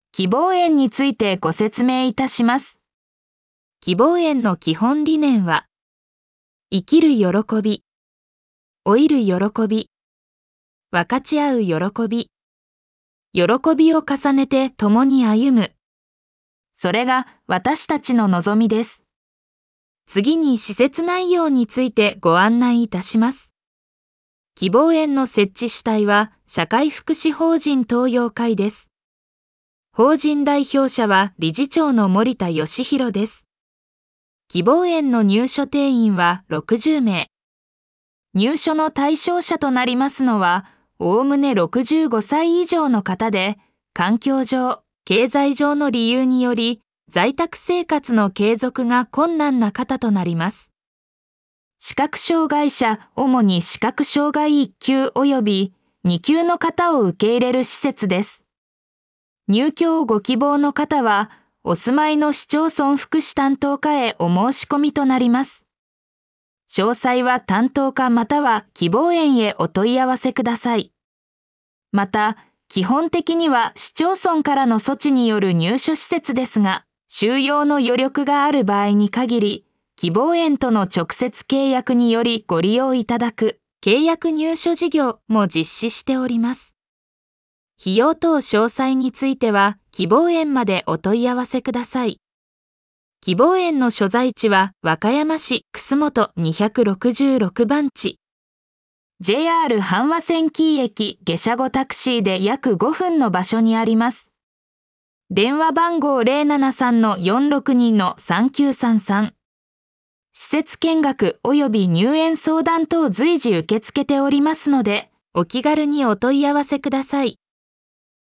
音声案内
AIvoiceinfo.mp3